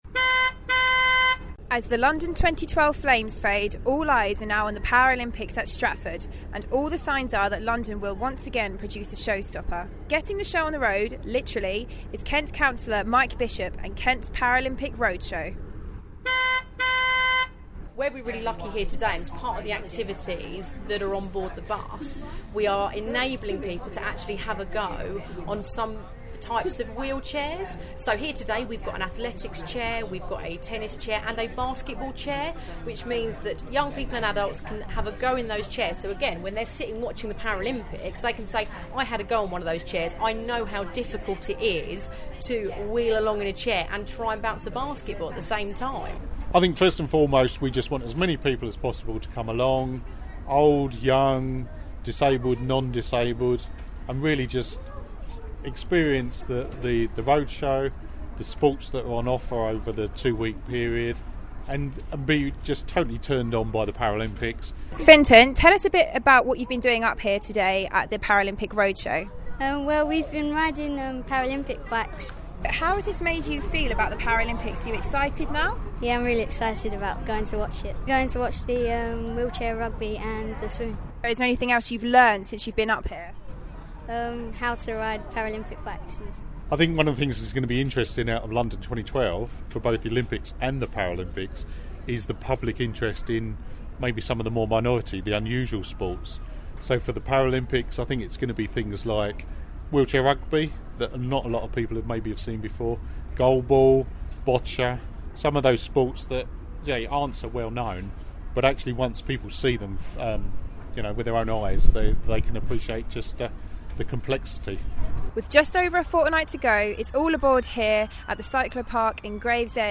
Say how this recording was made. Visiting a Kent Paralympic roadshow visits a Kent Paralympic Roadshow ahead of the London 2012 Paralympic games.